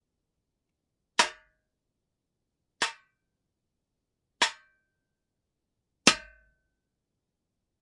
金属锡打 - 声音 - 淘声网 - 免费音效素材资源|视频游戏配乐下载
用Zoom H5＆amp;记录SGH6霰弹枪麦克风。